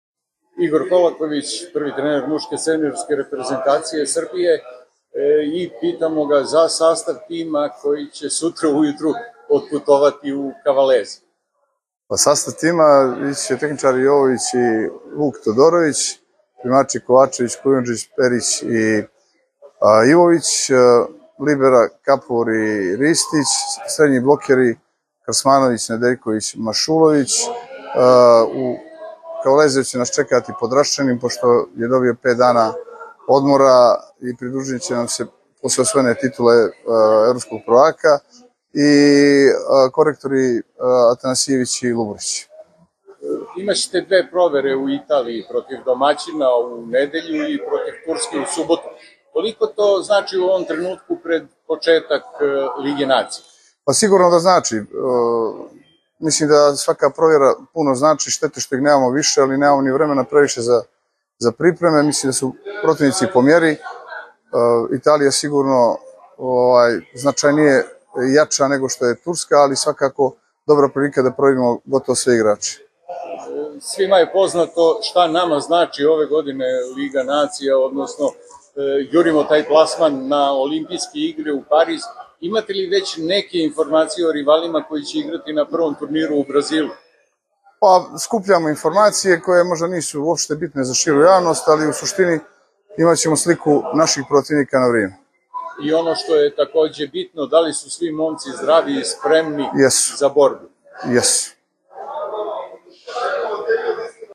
Izjava Igora Kolakovića